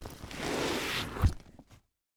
household
Duffle Bag Lift Ground 2